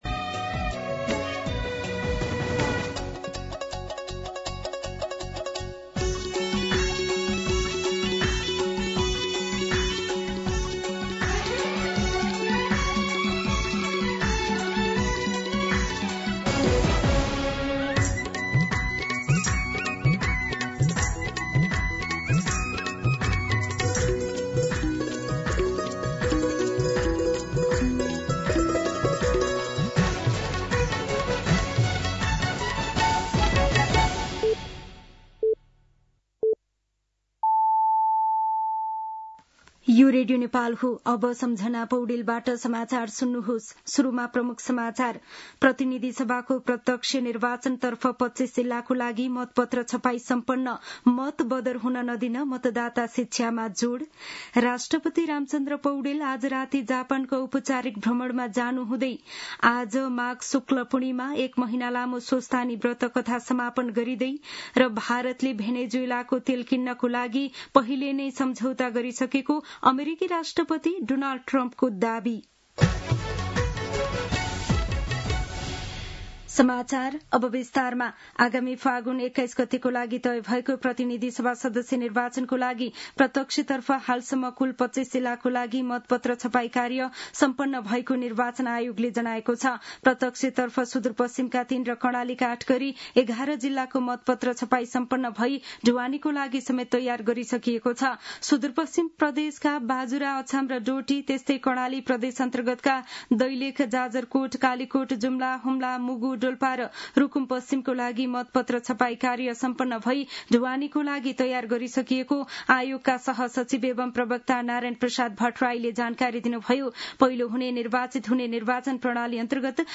दिउँसो ३ बजेको नेपाली समाचार : १८ माघ , २०८२